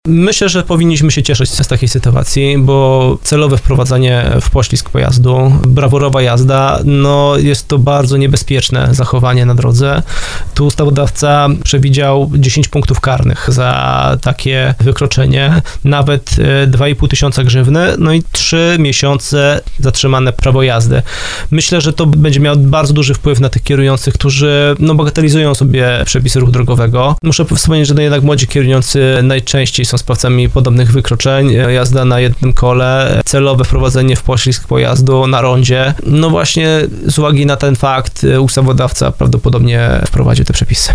Na antenie RDN Małopolska wyraził nadzieje, że nowe przepisy będa działać odstraszająco i zwiększą bezpieczeństwo na drogach.